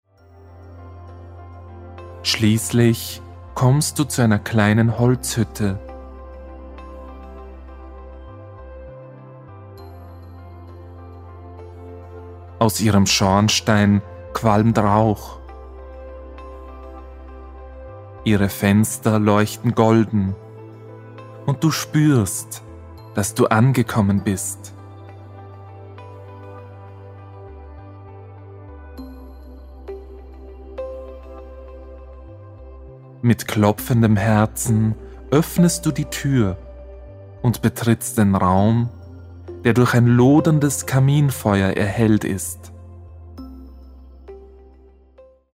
Inhalt: Die CD enthält 5 Meditationen für die Weihnachtszeit.